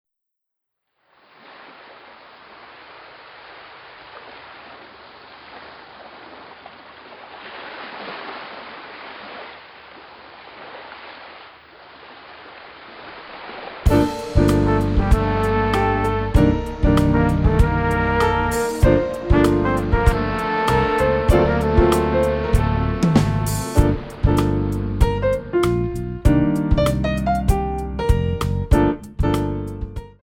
Jazz / Modern
4 bar intro
allegro